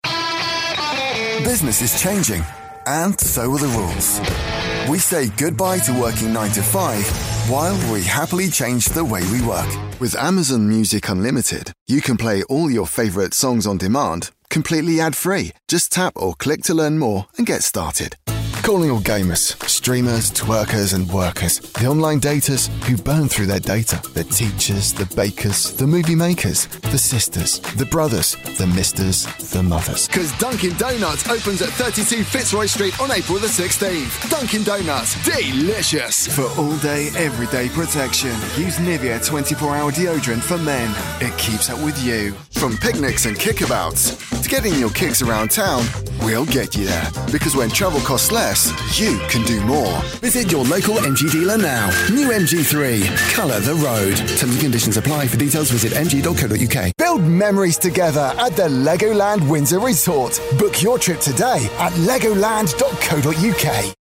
Natürlich, Cool, Zugänglich, Unverwechselbar, Warm
Kommerziell